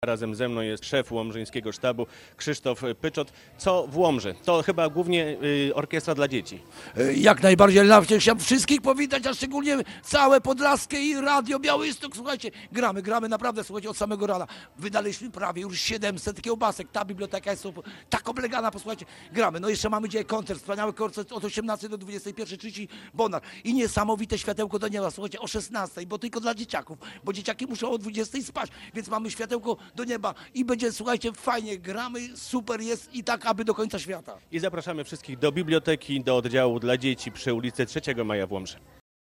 WOŚP w Łomży - relacja